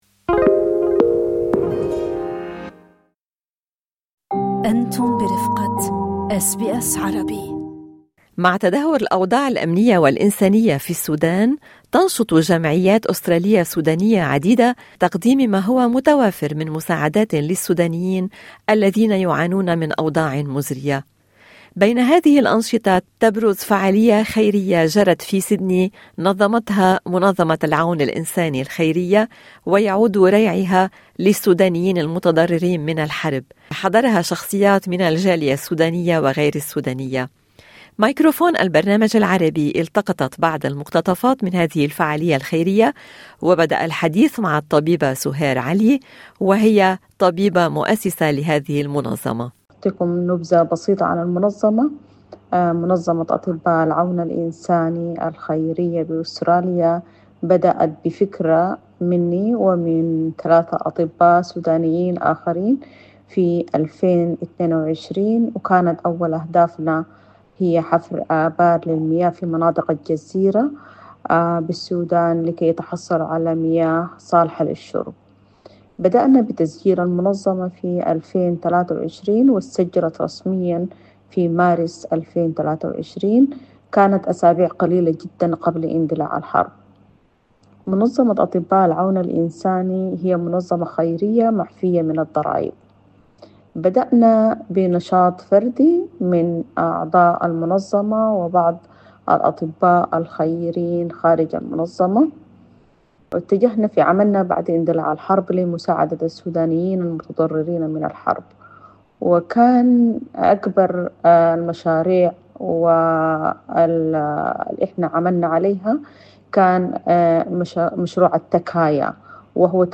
أقامت منظمة الأطباء الخيرية للمساعدات الإنسانية حفل عشاء خيري في سيدني لجمع التبرعات لصالح السودان والمتضررين من الحرب فيه حضره شخصيات عديدة من الجاليات السودانية والعربية في أستراليا. ميكروفون البرنامج العربي كان هناك وعاد ببعض المقتطفات.